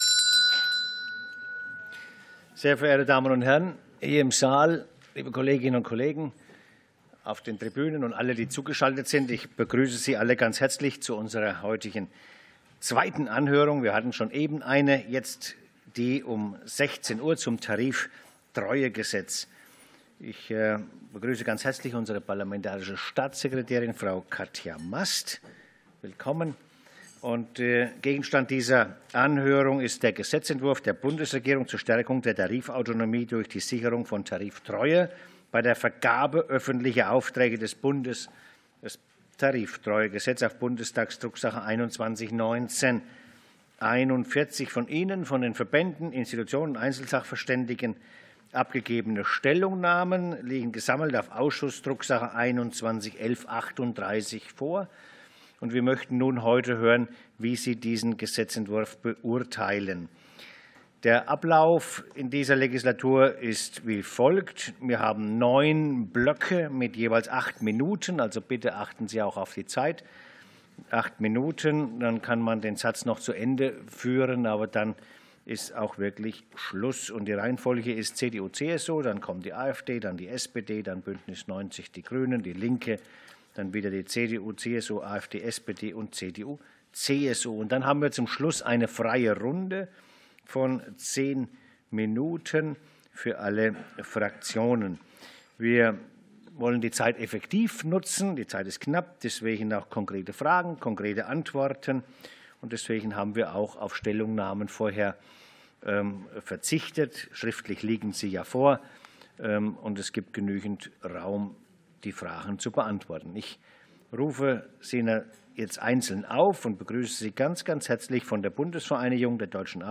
Anhörung zum Tariftreuegesetz